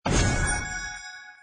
ui_interface_83.wav